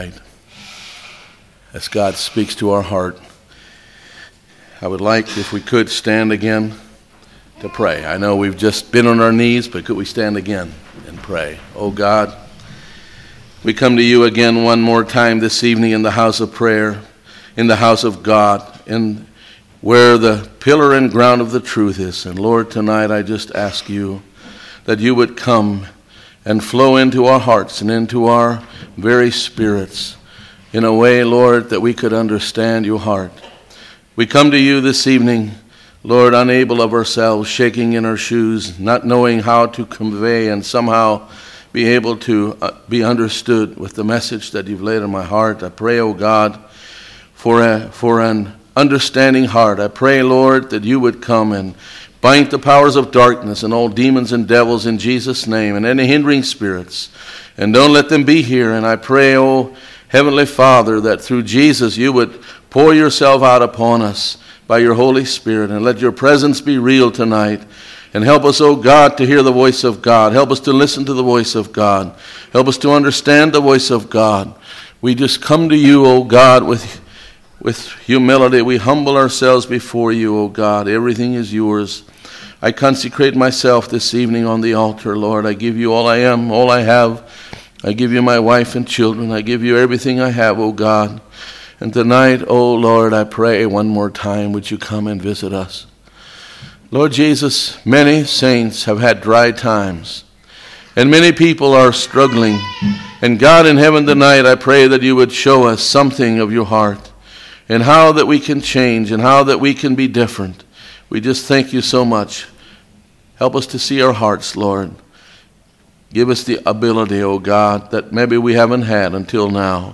Sermons
Congregation: Susquehanna Valley